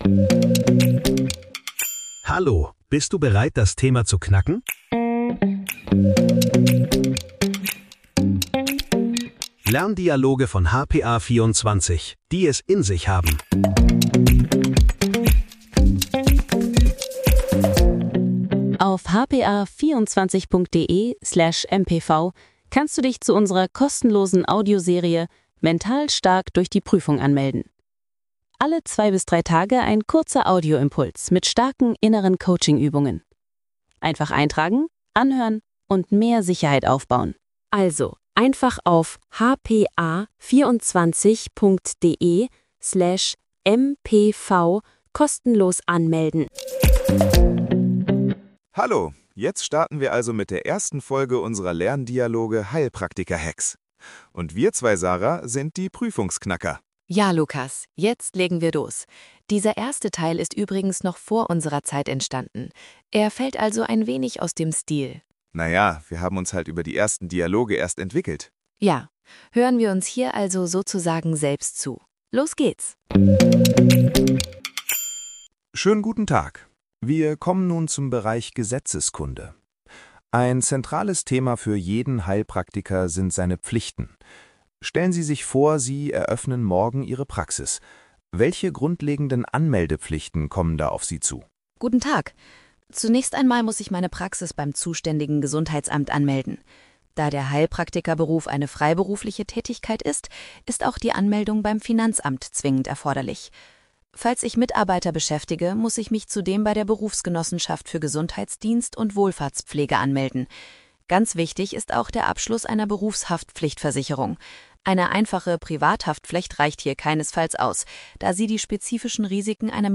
Lerndialoge für deinen Prüfungserfolg